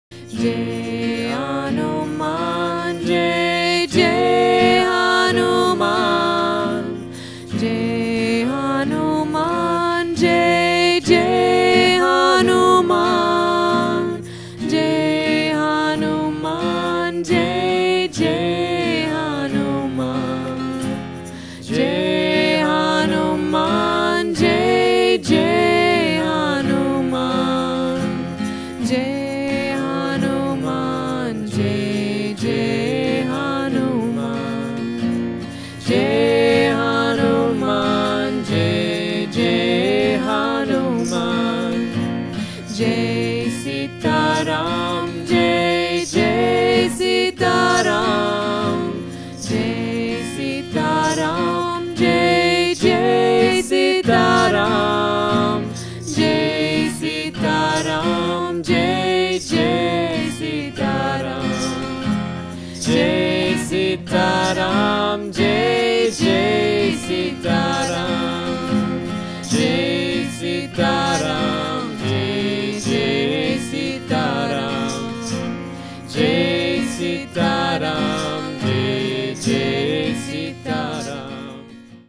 traditional chants from India
arranged to guitar.